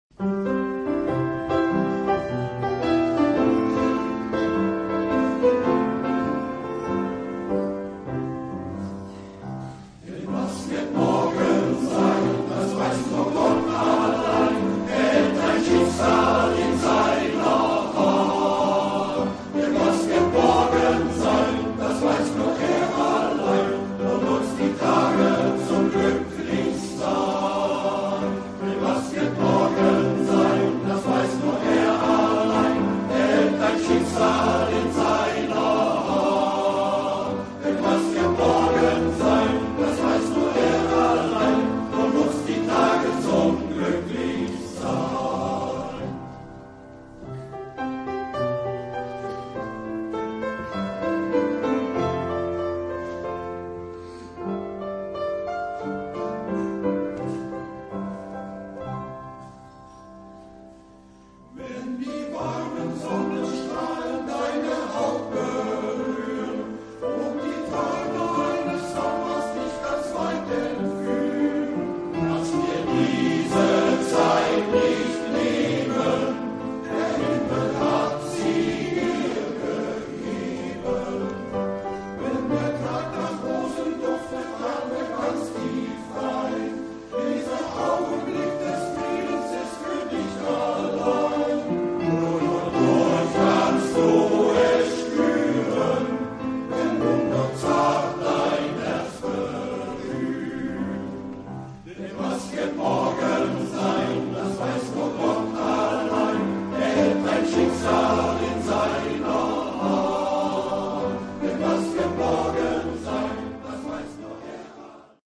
Ein lebendiger Chorsatz mit Klavier oder a cappella.